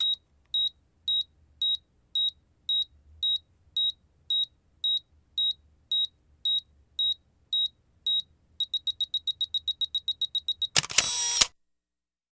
Звук Бипер пищит у камеры (отложенная съемка) (00:12)